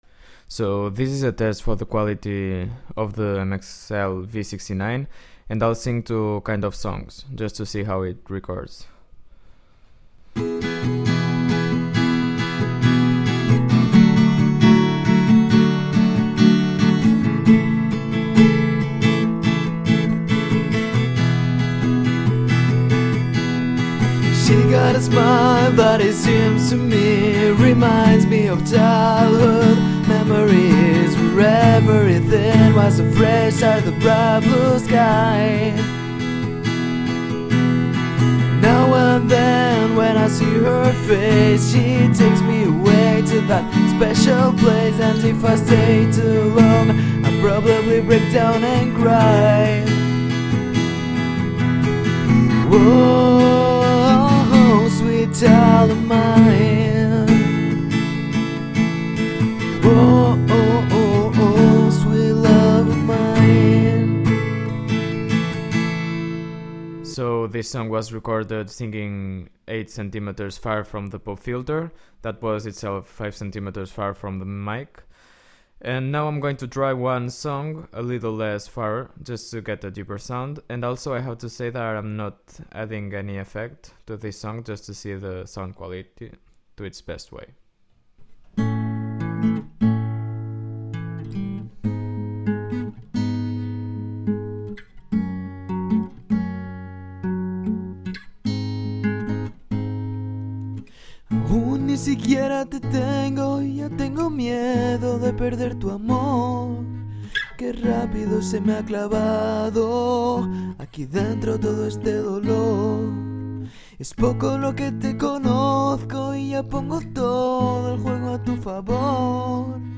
Condensor vs Dynamic (Without room treatment)
I didn't add any effects , I record in multitrack, first the guitar and after the voice